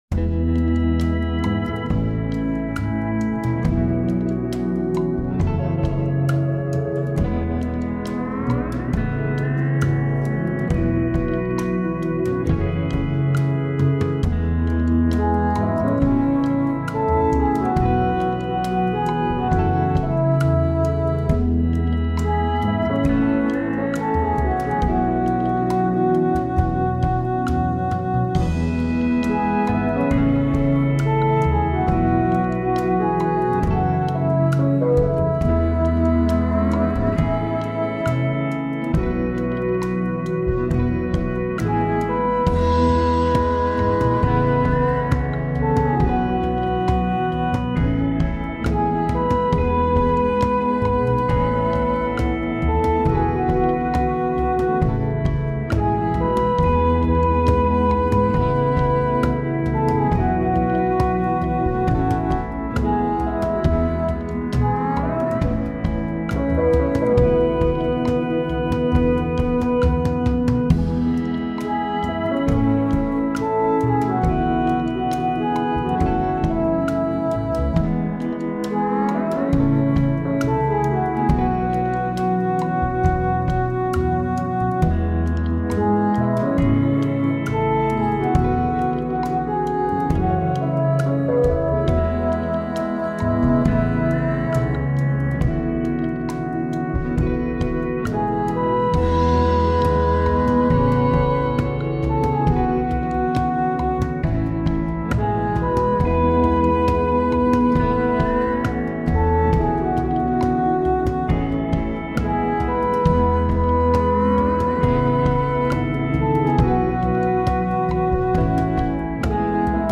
slow P&W genre